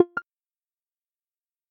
Звуки авторизации
Звук входа в приложение для разработчиков